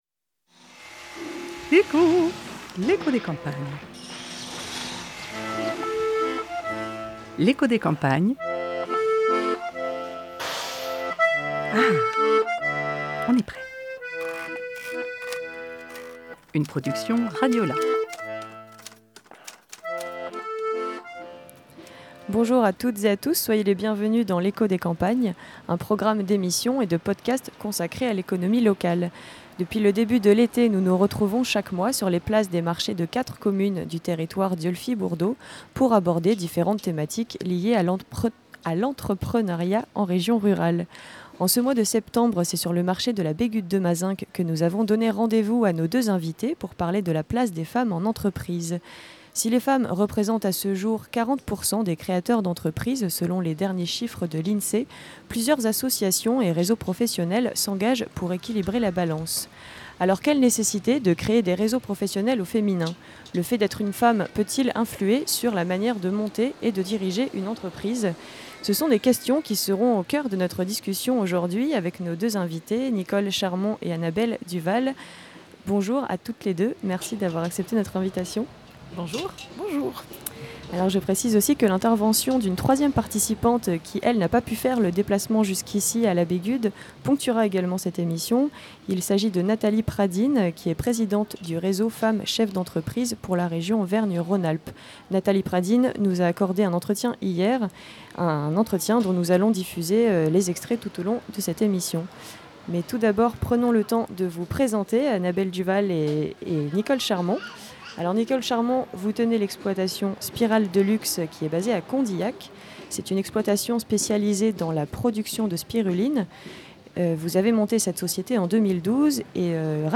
Pour ce cinquième plateau L’éco des campagnes, nous avons donné rendez-vous à nos deux invitées sur le marché de la Bégude-de-Mazenc. Cette émission était consacrée à l’entrepreneuriat au féminin. Le fait d’être une femme peut-il influer sur la manière de monter et de diriger une entreprise ?